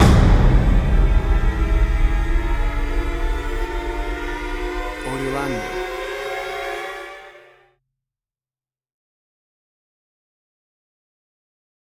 A dark and scary Horror Hit!